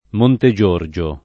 vai all'elenco alfabetico delle voci ingrandisci il carattere 100% rimpicciolisci il carattere stampa invia tramite posta elettronica codividi su Facebook Montegiorgio [ monte J1 r J o ] top. (Marche) — pn. loc. con -o- aperto